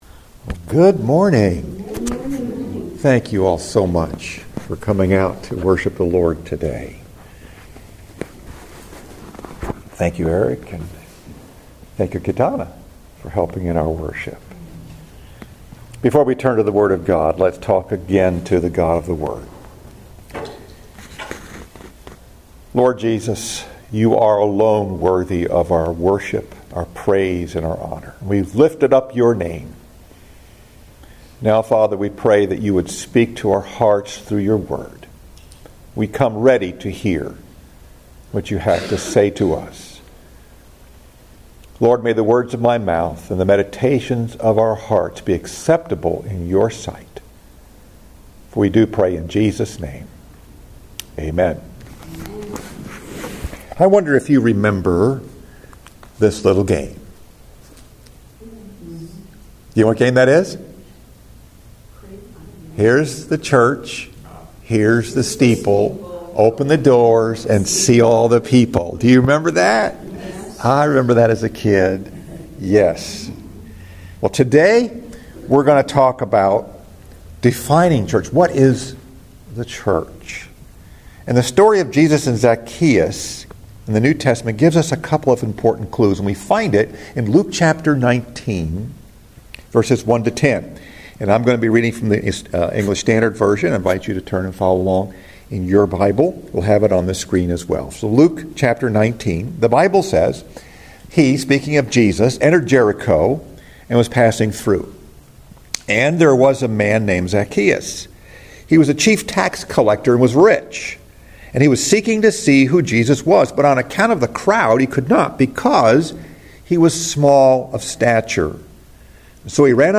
Message: “Defining Church” Scripture: Luke 19:1-10 Fifth Sunday of Lent